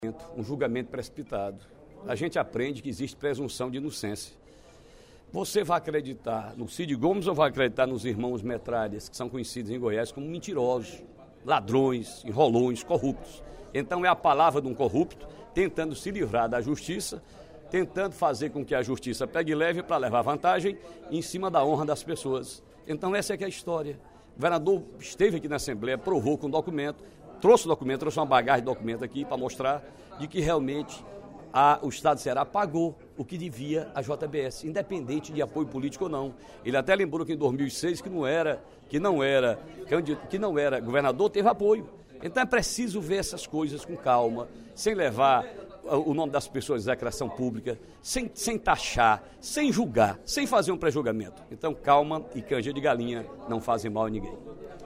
O deputado Ferreira Aragão (PDT) cobrou cautela, durante o primeiro expediente da sessão plenária desta quinta-feira (25/05), aos críticos do ex-governador do estado Cid Gomes.